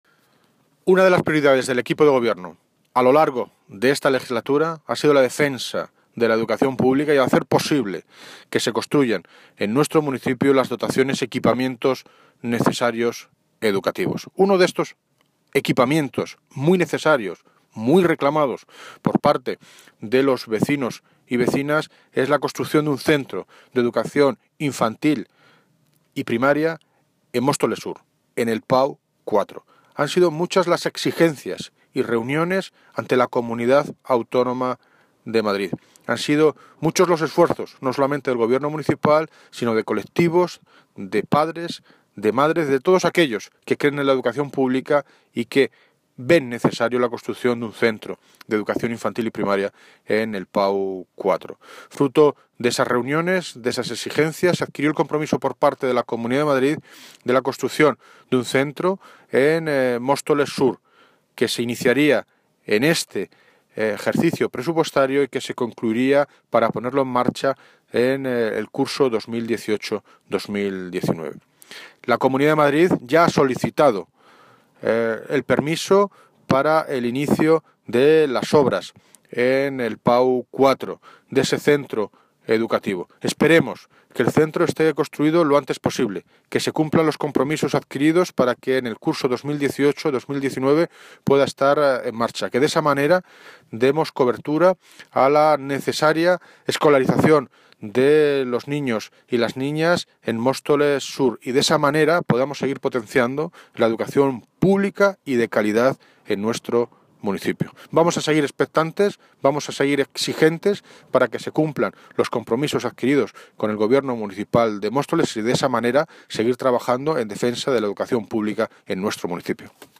Audio - David Lucas (Alcalde de Móstoles) Sobre Colegio PAU4